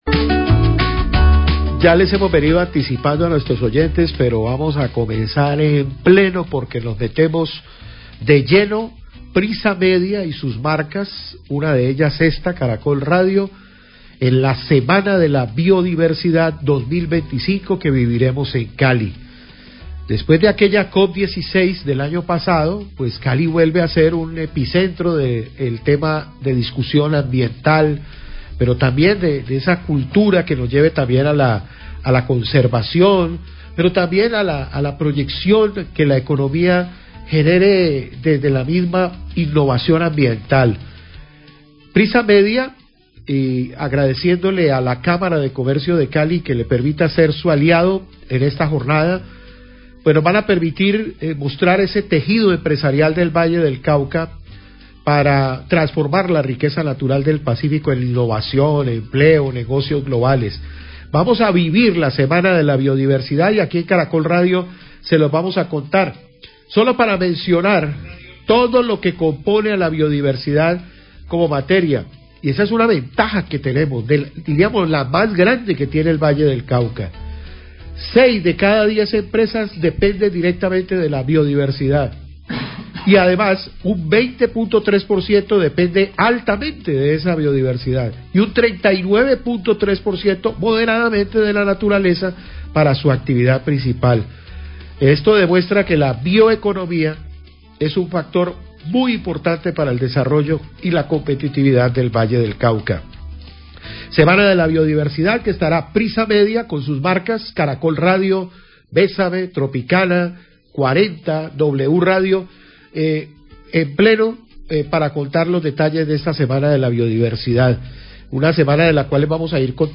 Encuesta callejera sobre qué es la biodiversidad
Radio
A propósito de la Semana de la Biodiversidad a celebrarse en Cali, se realiza encuesta callejera preguntando qué es la biodiversidad y se destaca la importancia de la misma para el desarrollo y el bienestar de la ciudadanía.